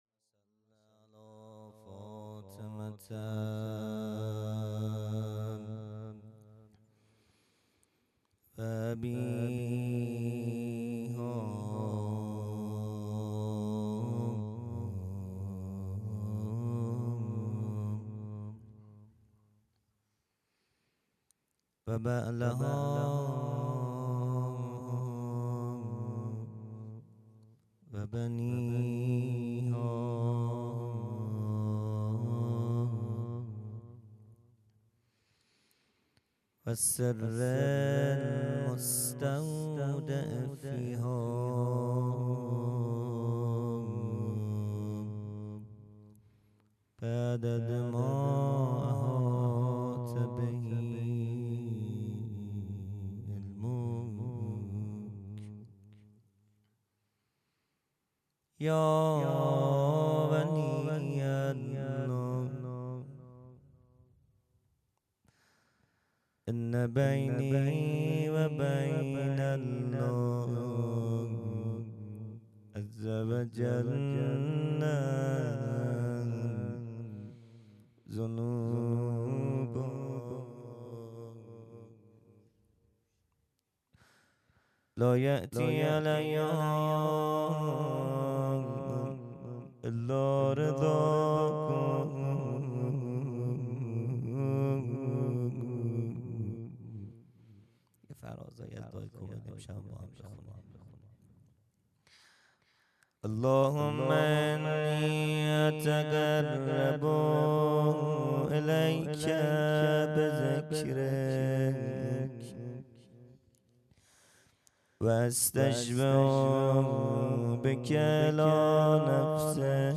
خیمه گاه - هیئت بچه های فاطمه (س) - مناجات و روضه | رو سیاه آمدم و در بدرم
جلسۀ هفتگی